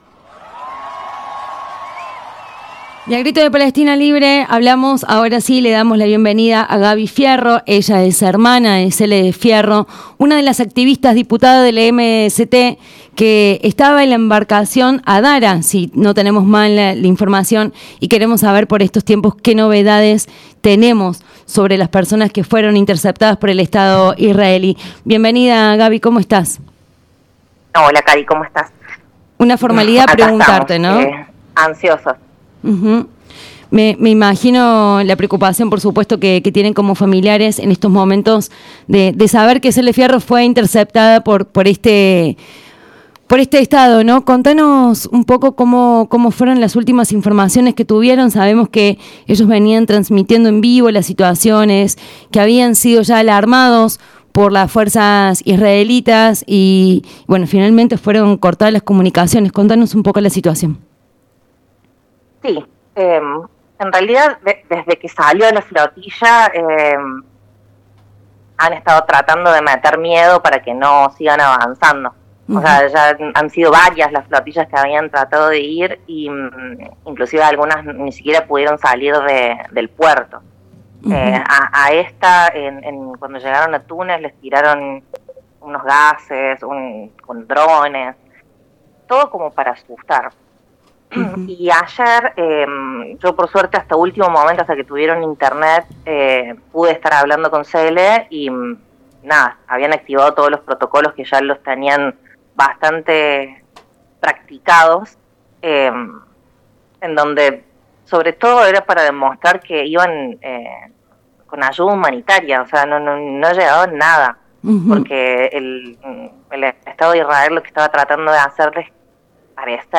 En diálogo con Radio Nexo